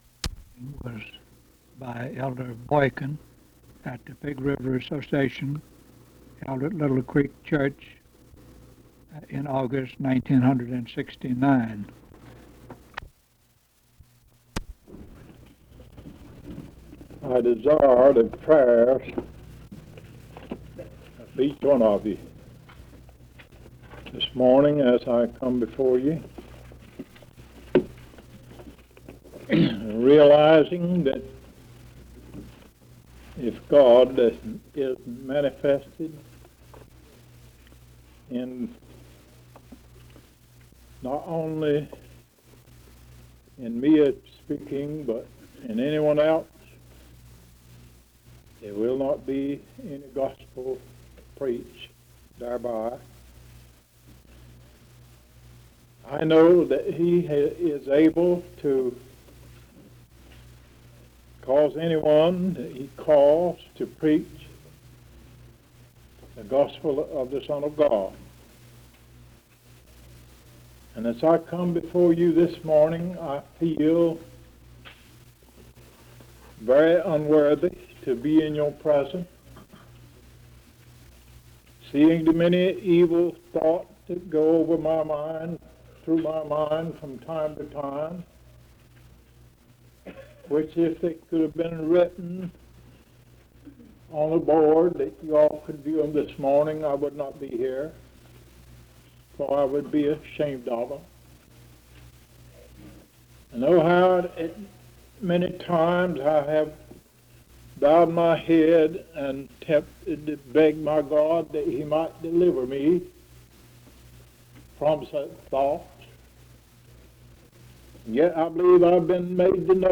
Sermons and singing from the 1969 Pigg River Association meeting, held at Little Creek Church